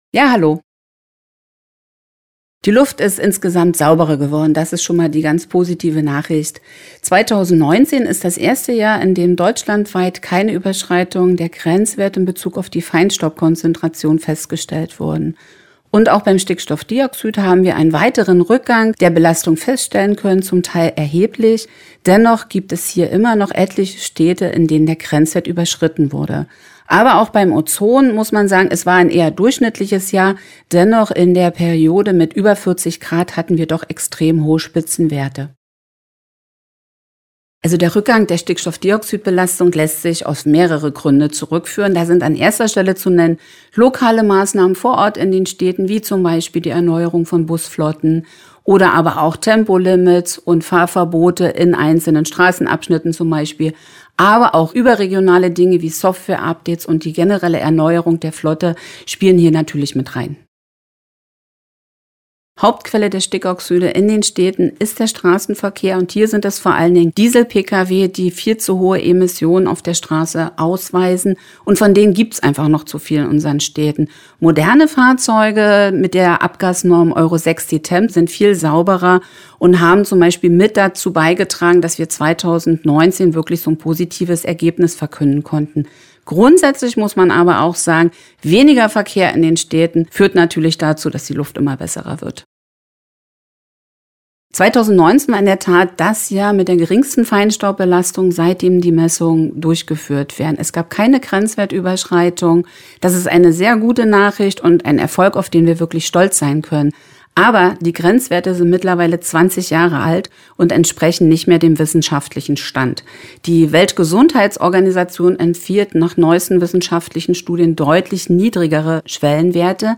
Interview: 2:41 Minuten